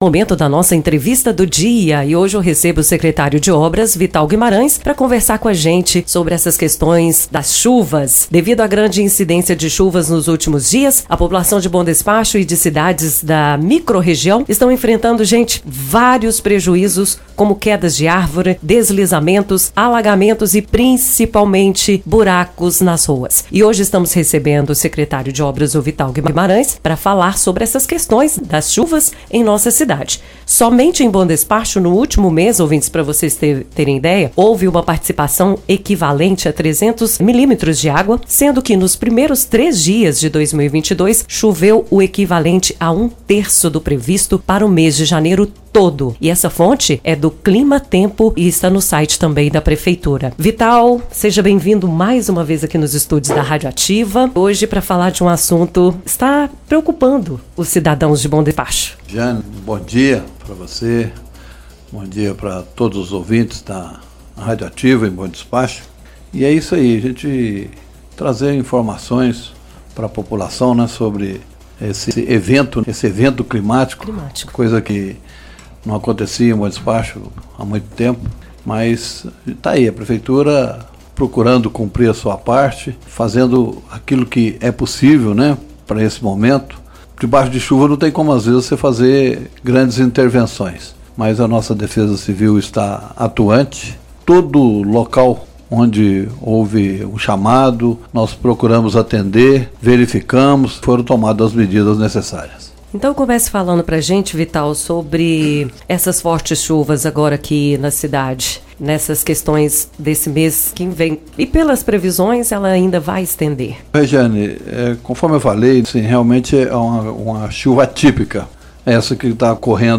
Hoje (dia 6) a Rádio Ativa entrevistou o secretário de Obras, Vital Guimarães. Na oportunidade, Vital falou sobre as chuvas fortes que vêm caindo em BD e o trabalho da Prefeitura para evitar transtornos.